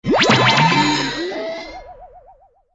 audio: Converted sound effects
SA_head_grow_back_only.ogg